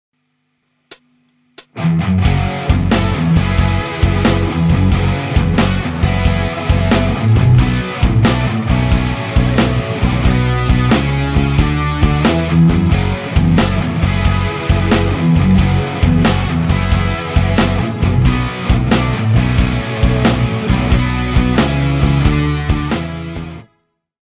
The soundclips are organized by amp type and feature a wide variety of tonal possibilities that are possible with our amps.
Classic Rhythm Overdrive AOR Molinelli Custom Lead 379Kb
Recording setup:Shure sm57, Soldano speakers, Dragoon Custom Speakers, Molinelli guitars